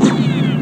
BSG_FX-Viper_Laser_02.wav